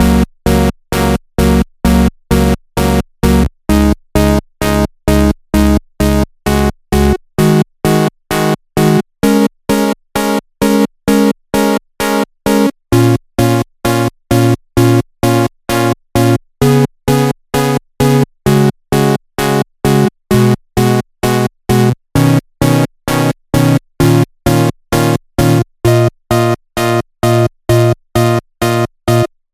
130深宅大院的灵魂性合成器
描述：同样的模式，但这次是一个合成器
Tag: 130 bpm Deep House Loops Synth Loops 4.97 MB wav Key : D